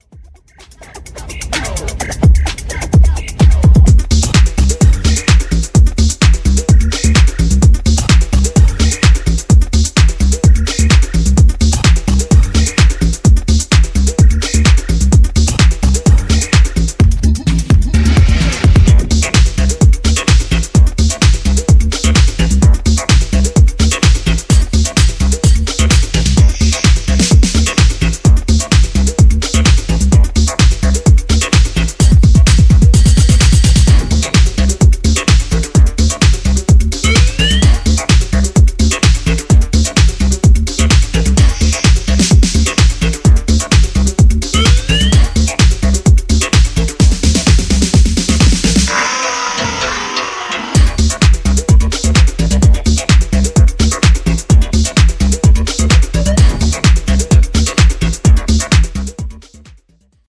80年代後期のアシッド〜イタロ・ハウスからインスピレーションを受けたという4曲入り。
Disco / Balearic House Italo House